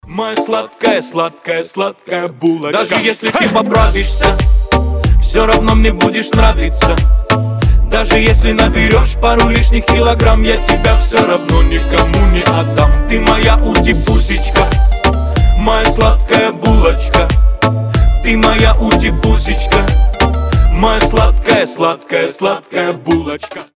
• Качество: 320, Stereo
ритмичные
милые